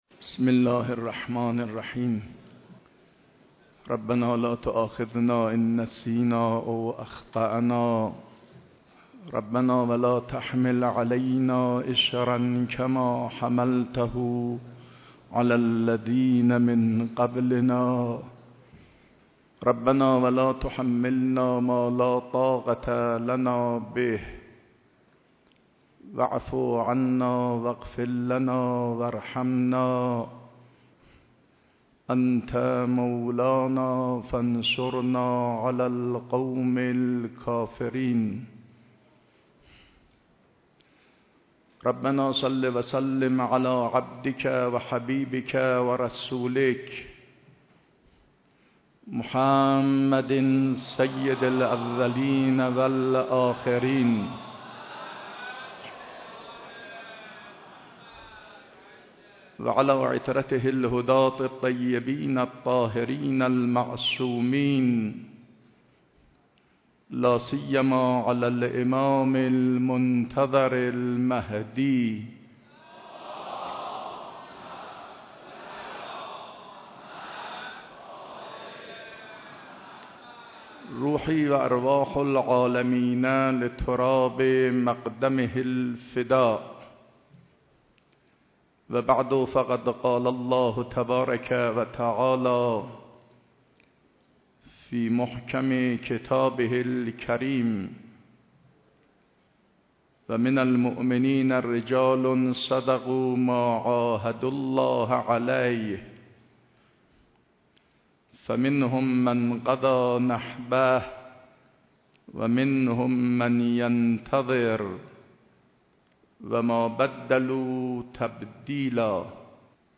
مراسم ترحیم عالم مجاهد و پارسا حضرت آیت الله مهدوی‌کنی برگزار شد
سخنرانی حجت الاسلام و المسلمین آقای علم الهدی